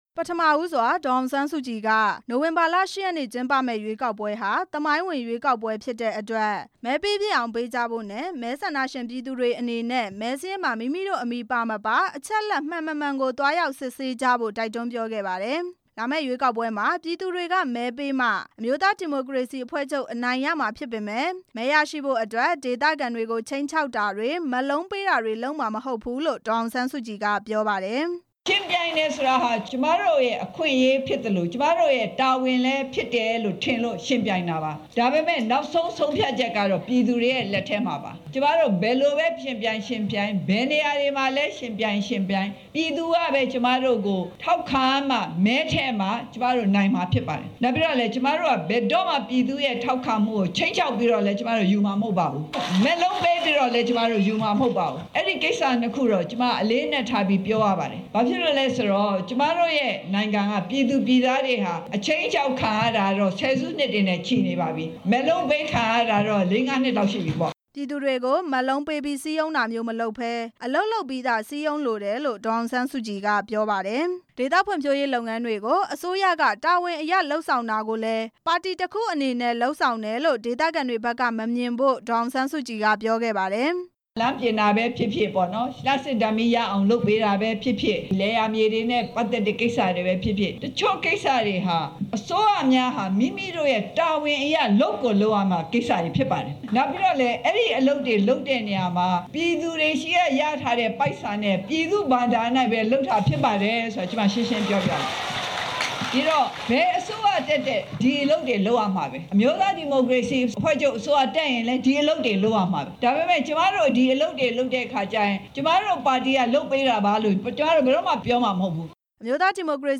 အမျိုးသားဒီမိုကရေစီအဖွဲွ့ချုပ် ဥက္ကဌ ဒေါ်အောင်ဆန်းစုကြည်ဟာ ဒီနေ့မနက်က ရှမ်းပြည်နယ်တောင် ပိုင်း၊ဟိုပုံးမြို့မှာ ဒေသခံတွေနဲ့တွေ့ဆုံပြီး ရွေးကောက်ပွဲဆိုင်ရာ အသိပညာပေးဟောပြောပွဲ ကျင်းပခဲ့ပါတယ်။ ဟောပြောပွဲအပြီးမှာ ဒေသခံတွေဘက်က သိလိုတာတွေကိုမေးမြန်းခဲ့ပြီး ဒေါ်အောင်ဆန်းစုကြည်က ဖြေကြားခဲ့ပါတယ်။